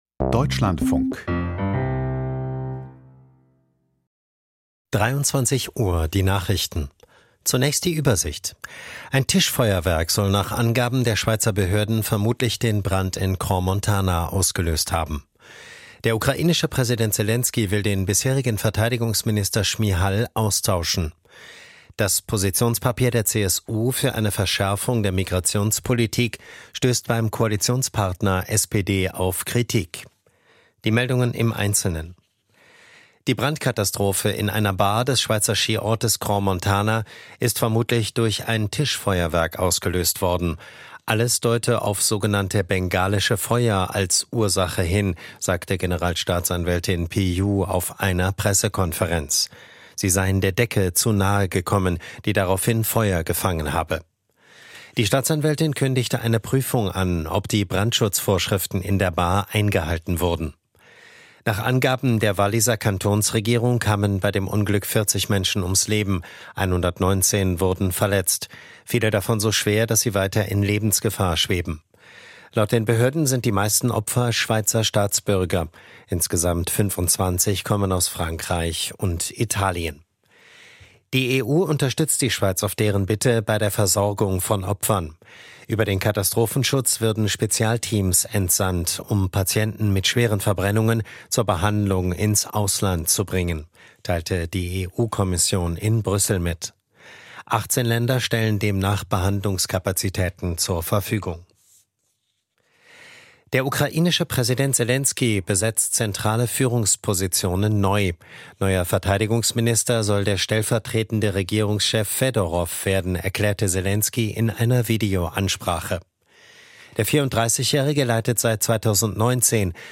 Die Nachrichten vom 02.01.2026, 23:00 Uhr
Aus der Deutschlandfunk-Nachrichtenredaktion.